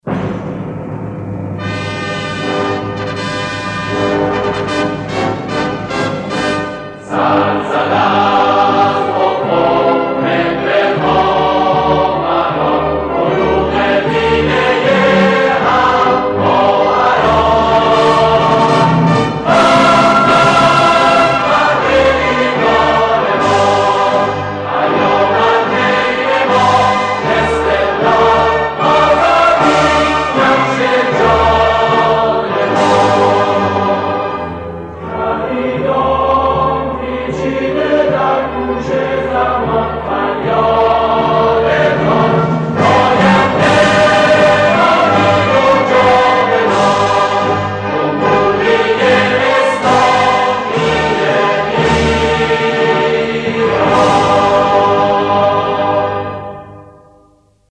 با کلام